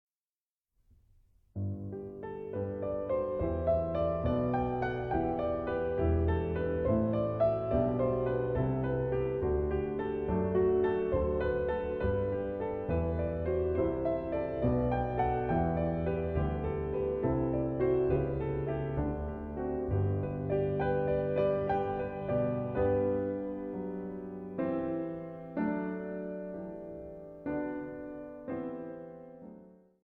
２台ピアノ版
緻密繊細な詩的世界からユーモラスな表情まで、
ひとつひとつの表現が納得ゆくまで磨きあげられて響き合う。
三重県総合文化センター